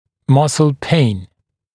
[‘mʌsl peɪn][‘масл пэйн]мышечная боль